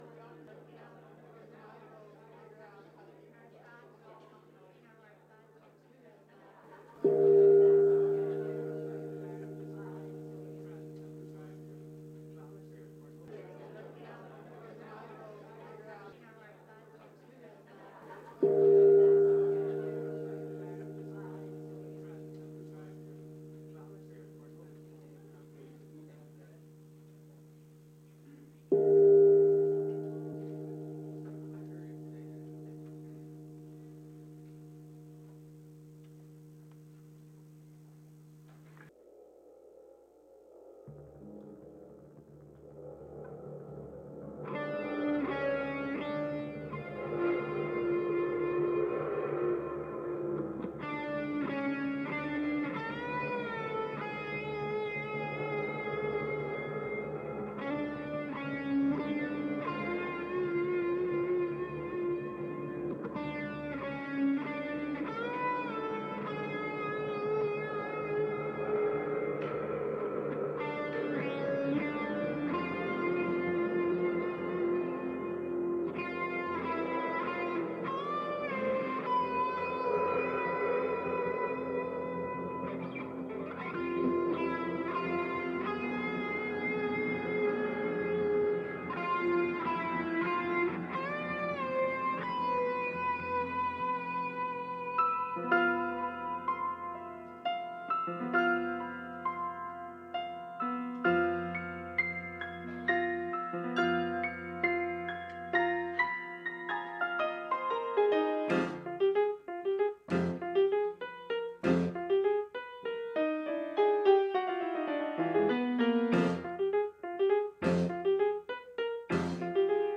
The audio recording below the video clip is an abbreviated version of the service. It includes the Meditation, Message, and Featured Song, and will be posted after editing.
This immersive experience is brought to life by a talented ensemble of musicians and singers, with narration